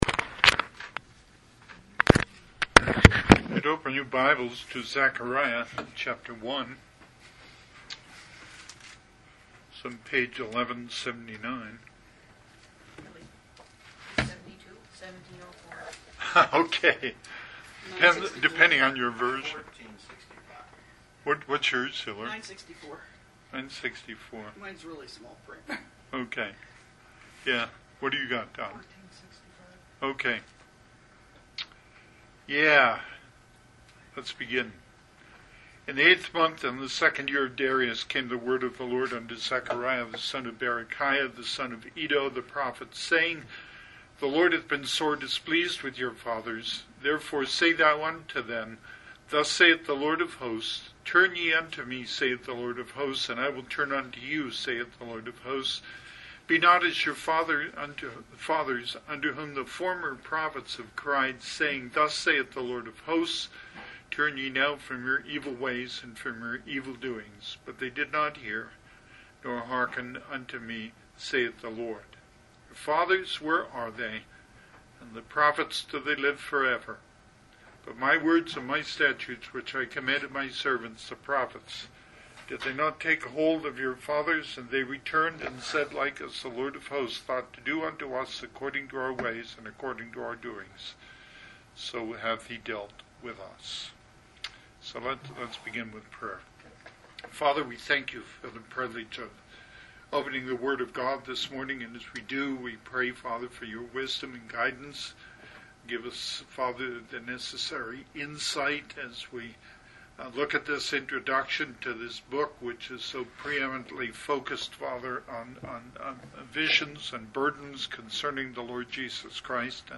Passage: Zechariah 1 Service Type: Sunday AM